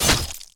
Sfx Player Stabbed Spikes Sound Effect
sfx-player-stabbed-spikes.mp3